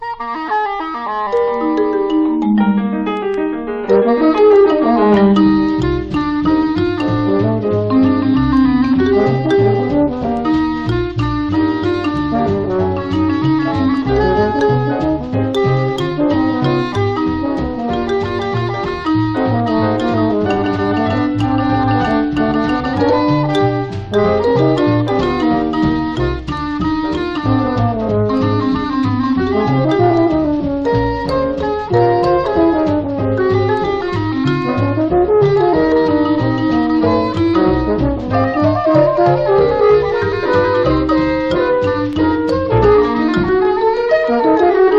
Jazz　USA　12inch　33rpm　Mono